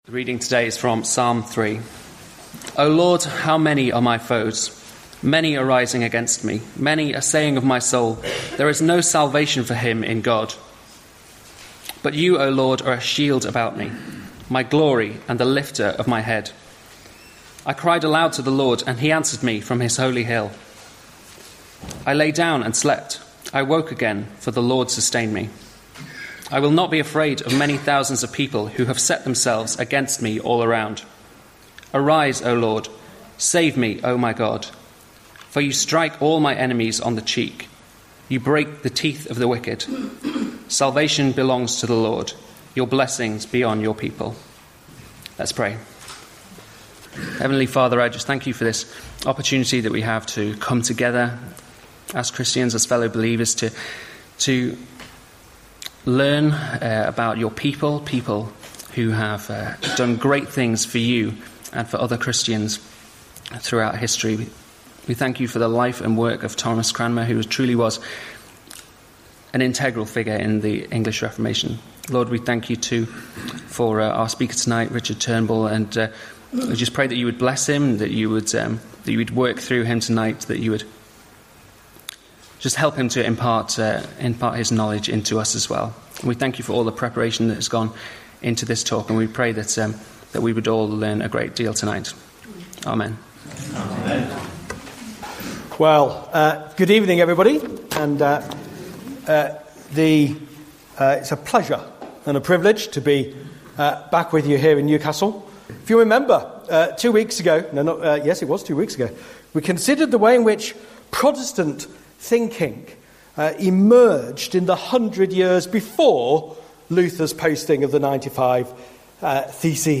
2018 Autumn Lectures